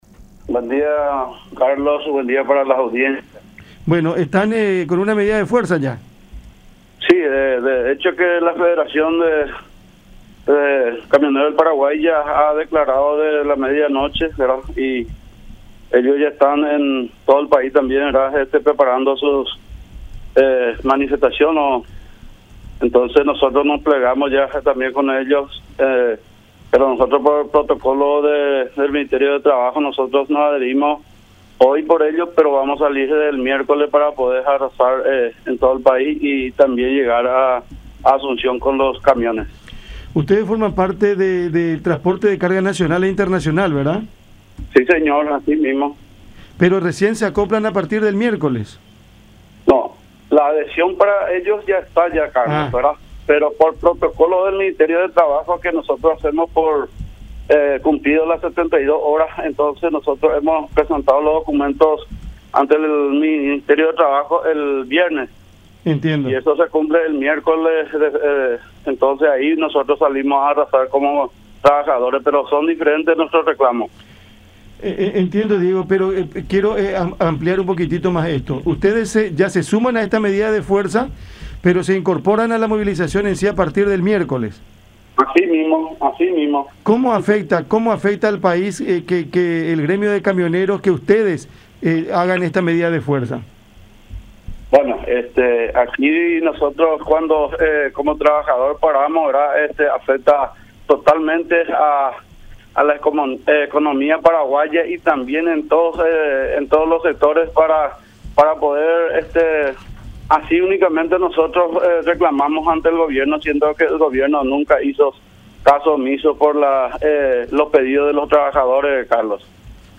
en diálogo con Cada Mañana por La Unión.